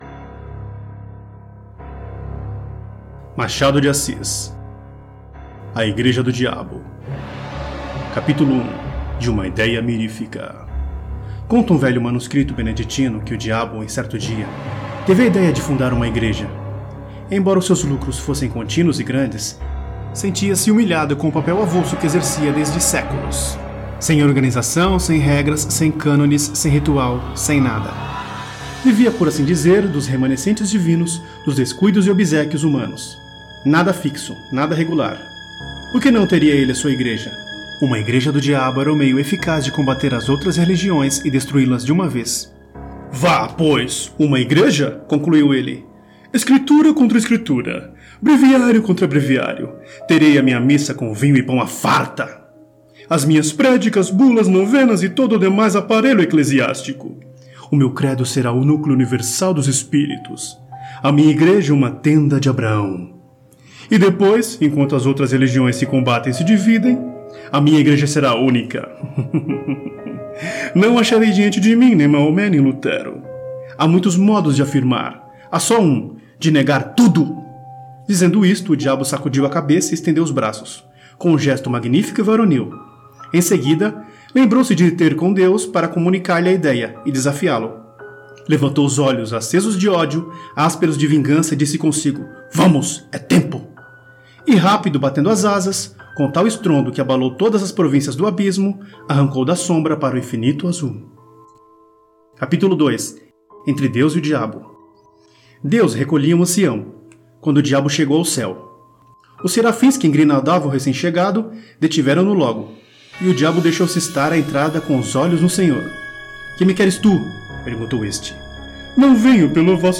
Leitura dramática